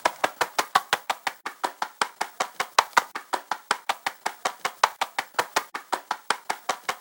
File:Sfx creature penguin hop wing flap 01.ogg - Subnautica Wiki
Sfx_creature_penguin_hop_wing_flap_01.ogg